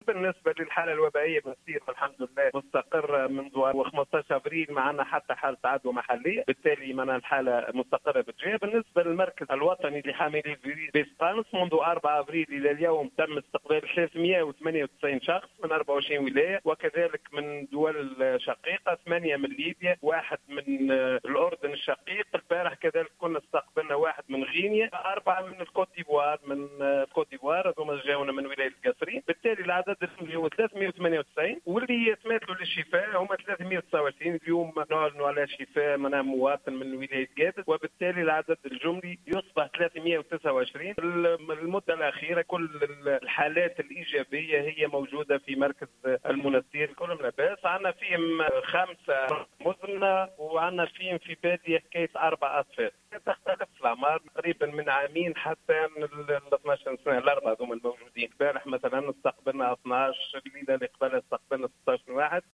أكد المدير الجهوي للصحة بالمنستير، المنصف الهواني في تصريح اليوم لـ"الجوهرة أف أم" تسجيل حالة شفاء جديدة بالمركز الوطني لحاملي فيروس "كورونا" بسقانص (مواطن من ولاية قابس) ليرتفع بذلك عدد المتعافين إلى 329.